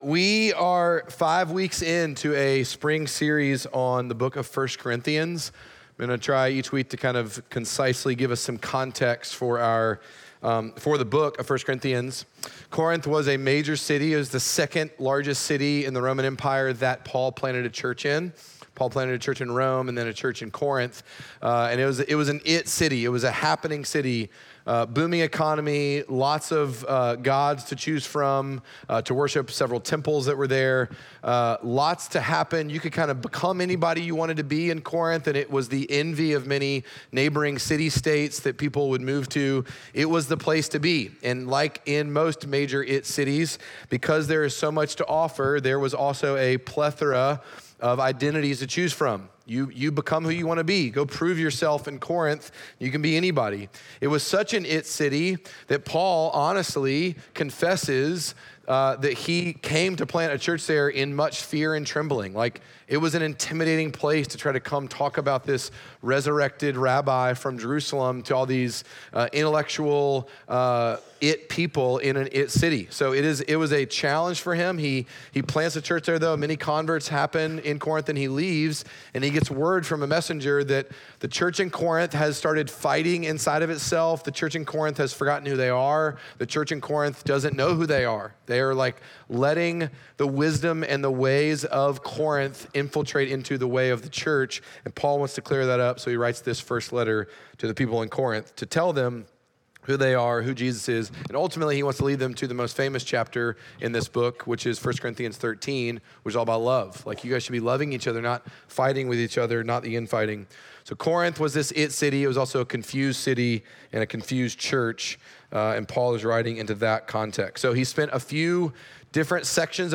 Midtown Fellowship 12 South Sermons Proof of Life Feb 23 2025 | 00:45:54 1x 00:00 / 00:45:54 Subscribe Share Apple Podcasts Spotify Overcast RSS Feed Share Link Embed